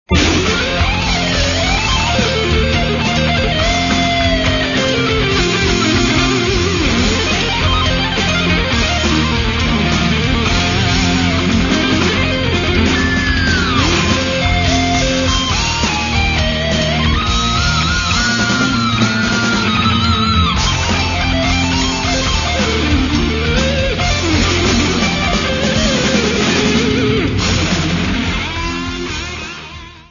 Ils sont au format mp3, 32 kbps, 22 KHz, mono.